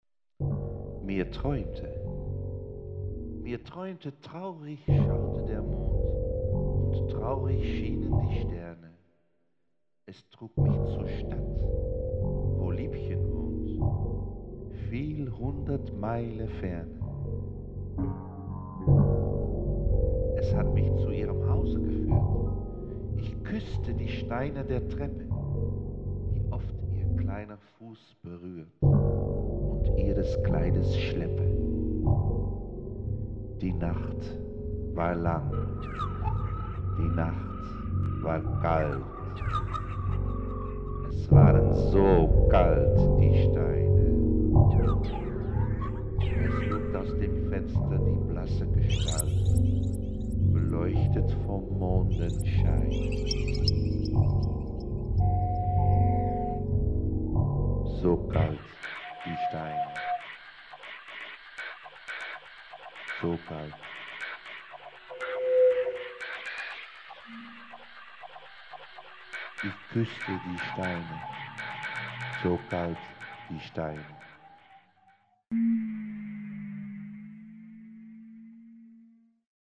Live! at The Van Gogh Museum
spoken word
laptop, objects, bass, voice